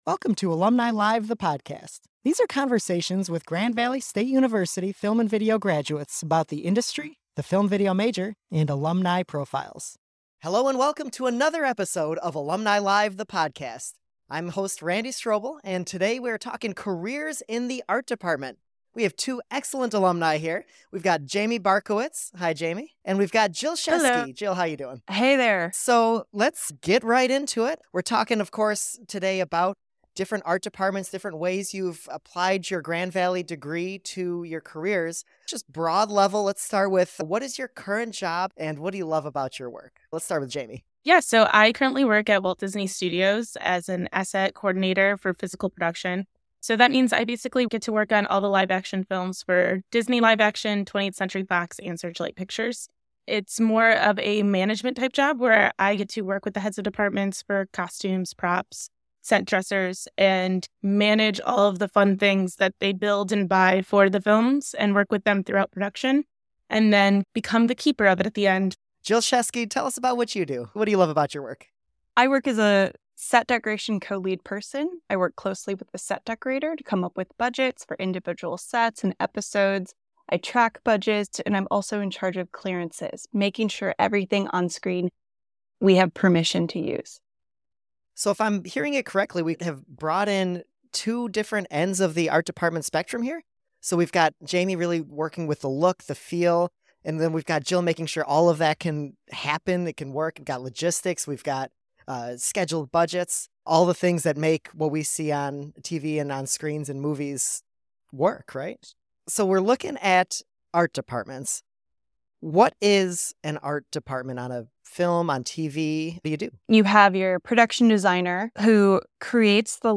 ART DEPARTMENT WORK IN LA: A Conversation